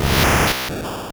Cri d'Abra dans Pokémon Or et Argent.